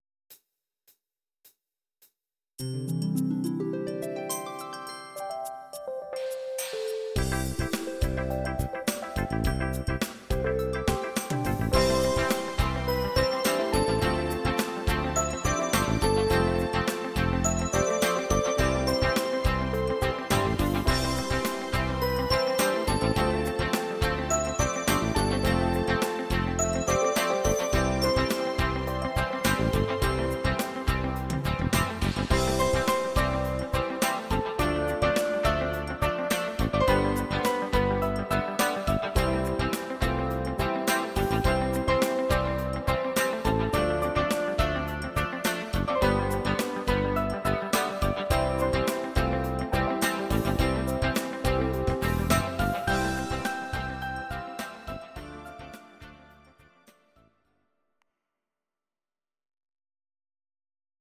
Please note: no vocals and no karaoke included.
Your-Mix: Instrumental (2073)